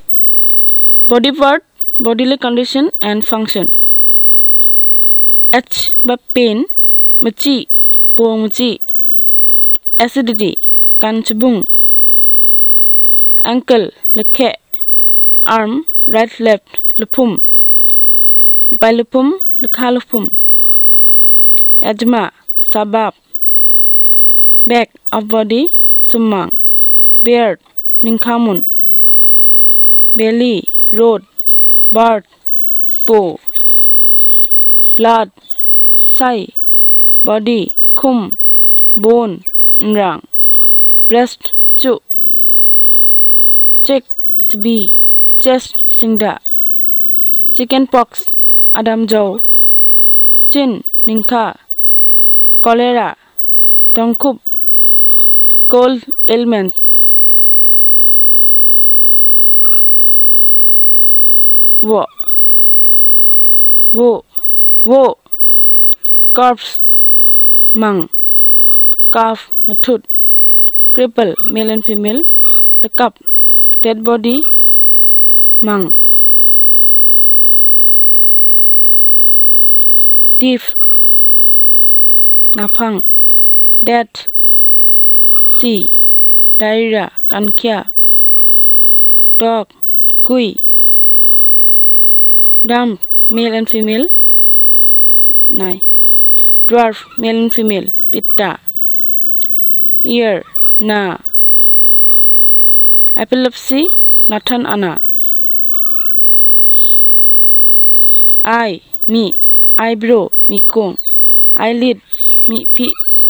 NotesThis is an elicitation of words about human body parts, bodily condition and function.